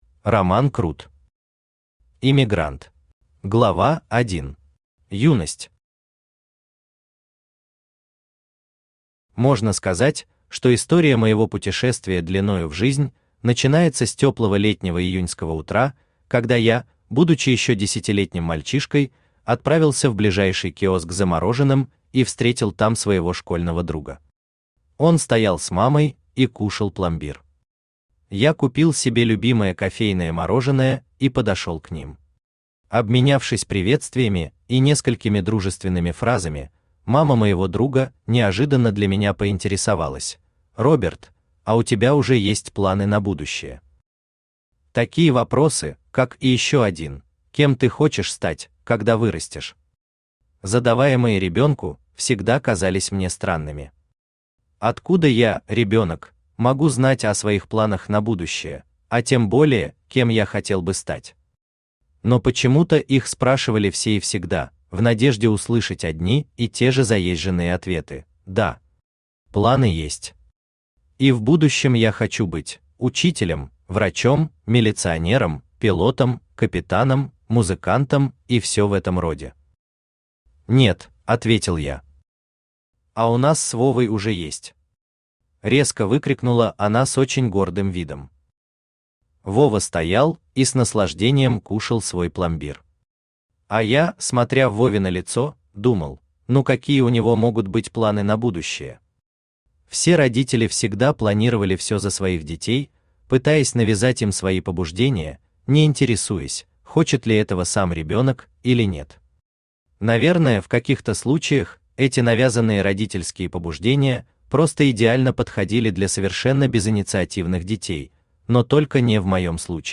Aудиокнига Иммигрант Автор Роман Крут Читает аудиокнигу Авточтец ЛитРес.